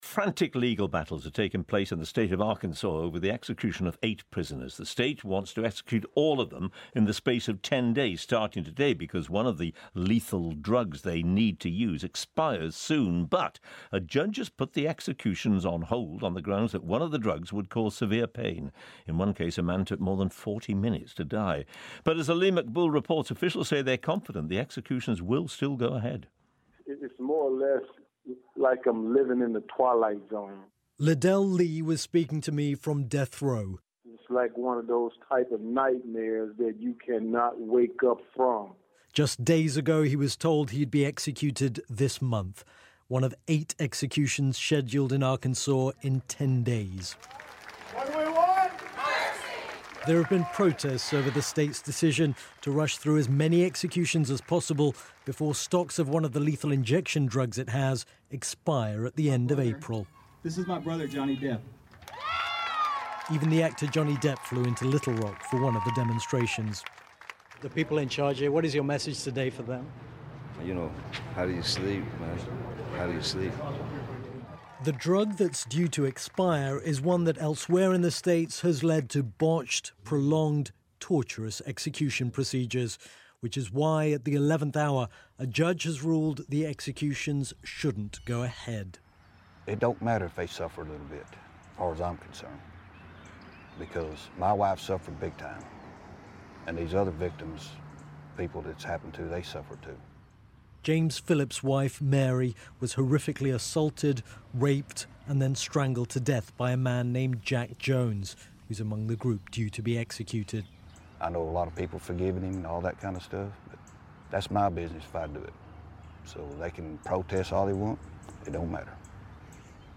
Our report for BBC Radio 4's Today Programme, Monday 17th April 2017